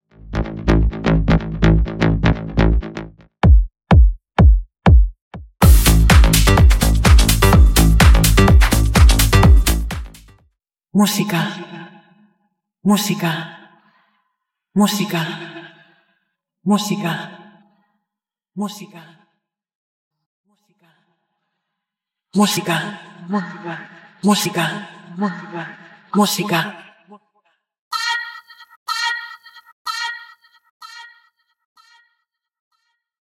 Instrumental
Leading Vocals
Percussion & Drums Stem
Subwoofer & Bassline Stem